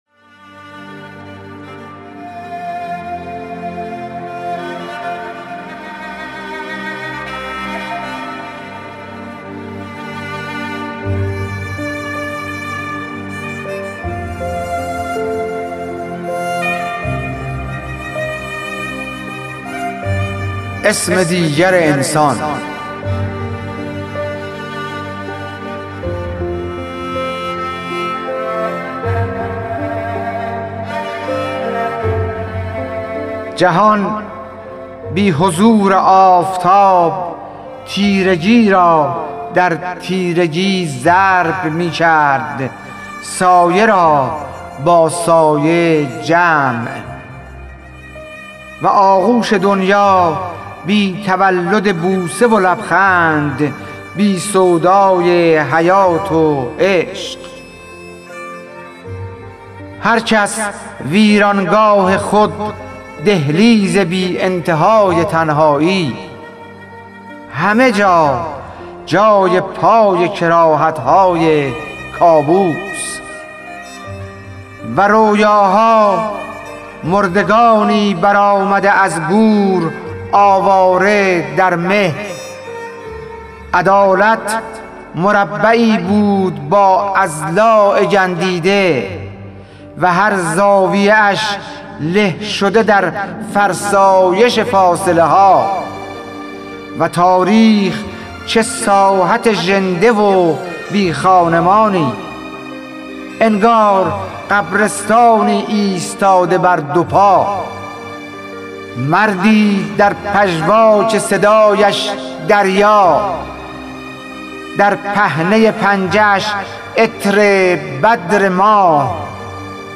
خوانش شعر سپید عاشورایی/ ۱